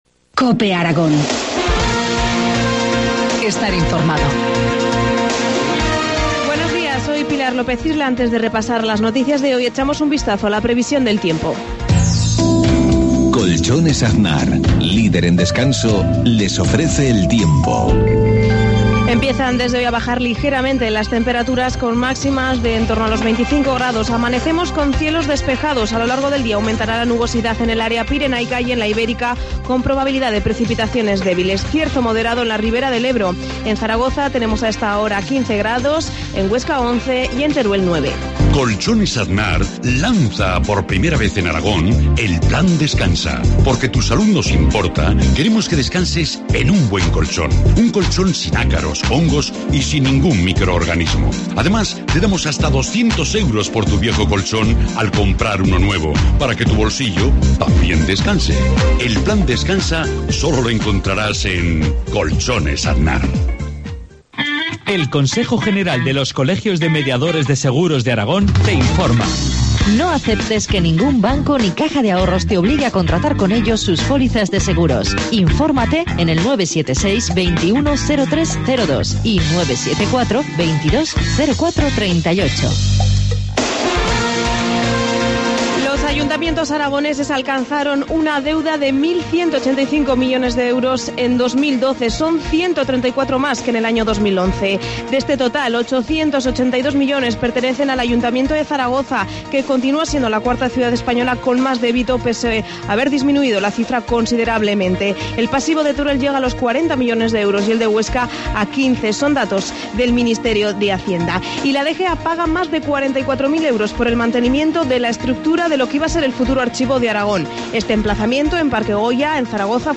Informativo matinal, jueves 18 de abril, 7.53 horas